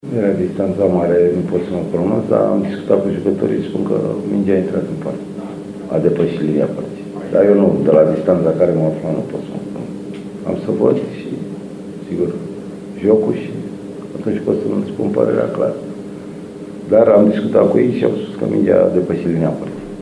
Florin Marin nu se pronunță asupra golului anulat din finalul meciului, dar spune că jucătorii susțin că mingea a trecut de linia porții: